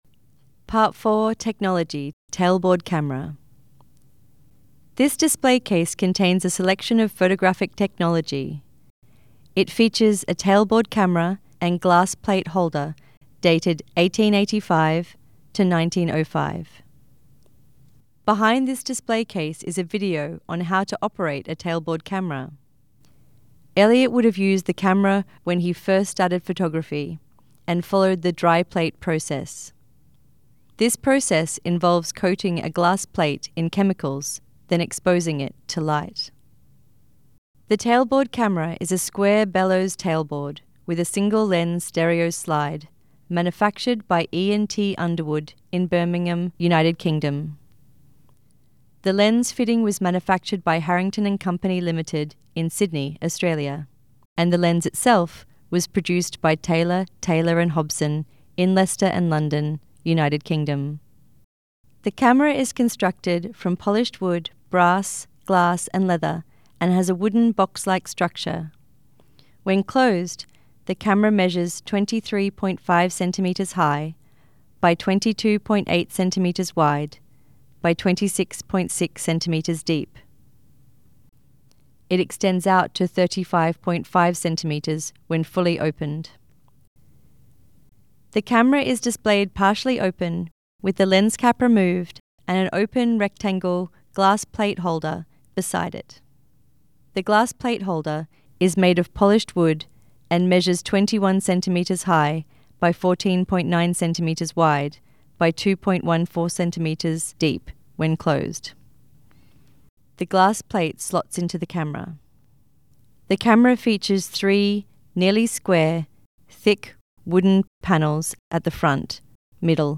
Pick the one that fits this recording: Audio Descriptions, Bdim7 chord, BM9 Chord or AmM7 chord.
Audio Descriptions